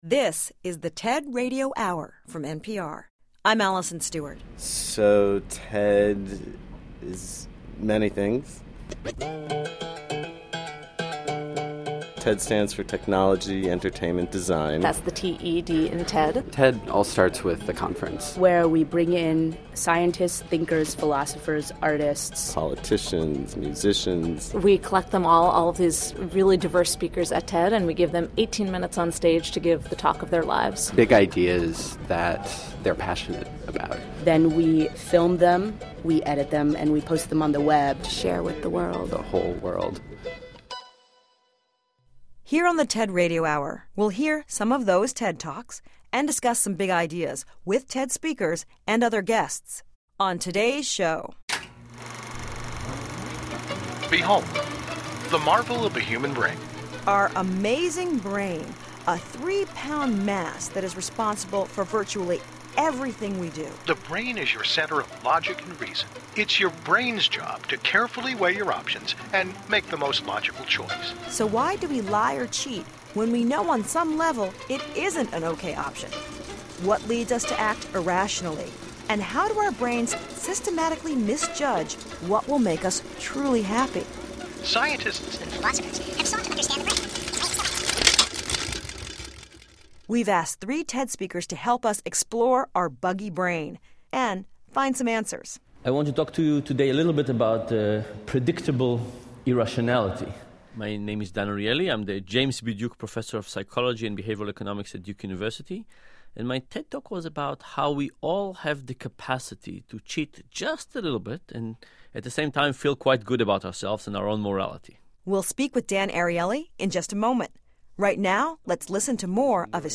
What tricks do our minds play when we think it’s okay to lie, cheat, or steal? How in control are we of our own decisions? And why do our brains systematically misjudge what makes us happy? Host Alison Stewart talks about our buggy brain with TED Speakers Dan Ariely, Paul Bloom, and Dan Gilbert.